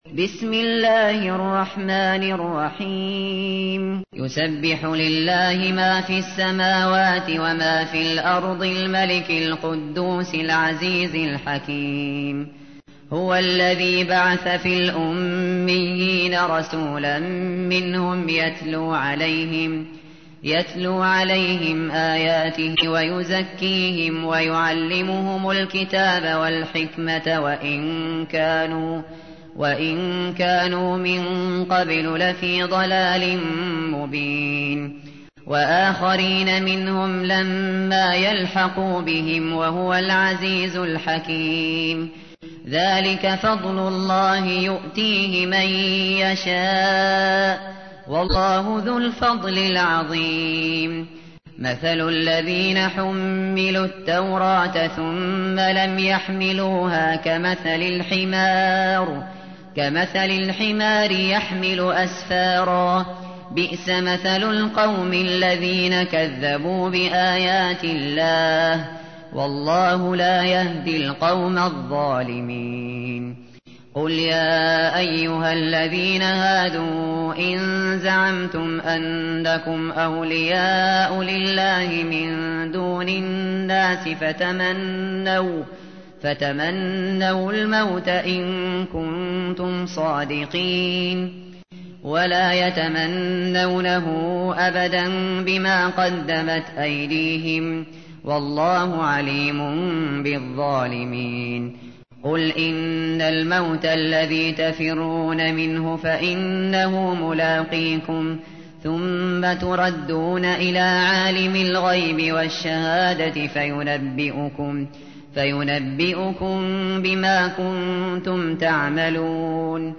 تحميل : 62. سورة الجمعة / القارئ الشاطري / القرآن الكريم / موقع يا حسين